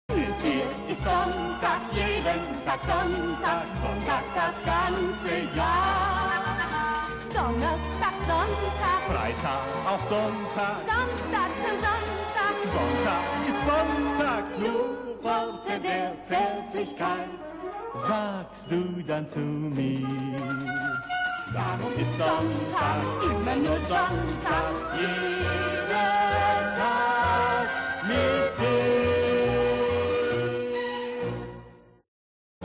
Musikalisches Lustspiel in sechs Bildern